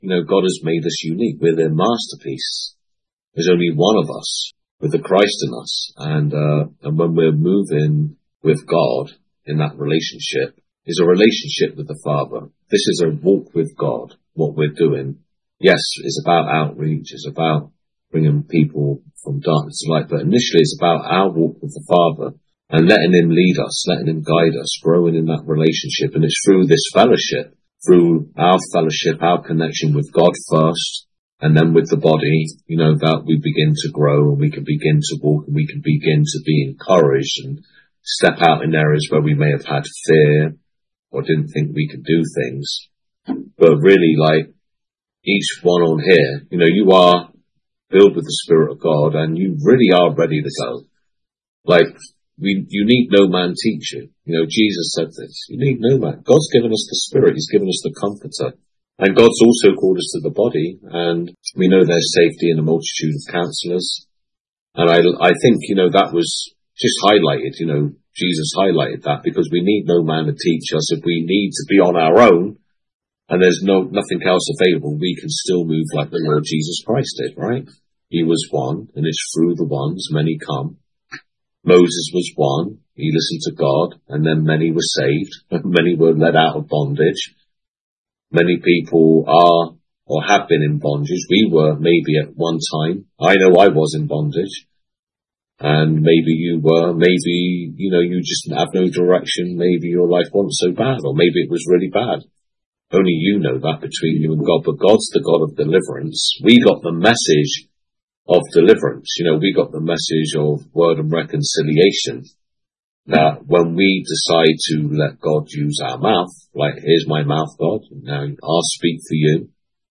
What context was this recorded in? From this Aprils Monthly Leaders Meeting